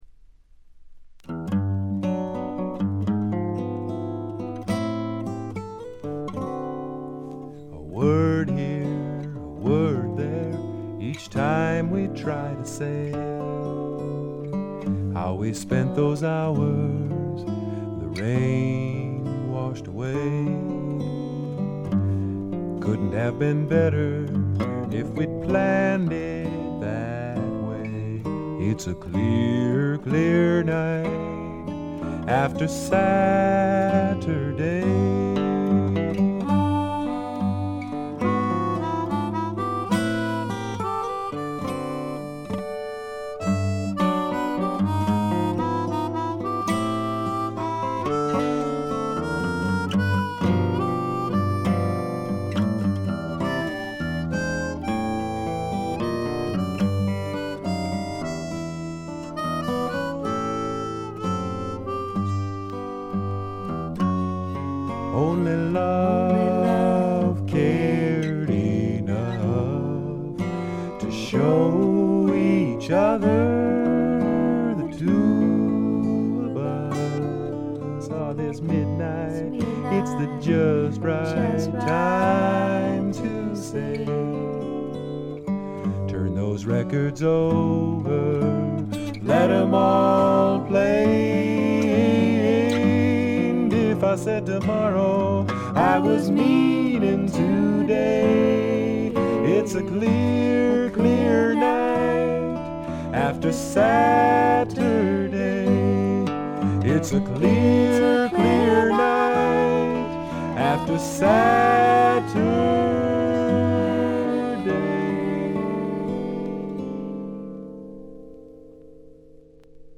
ほとんどノイズ感無し。
全体に静謐で、ジャケットのようにほの暗いモノクロームな世界。
試聴曲は現品からの取り込み音源です。
Vocals, Guitars, Harmonica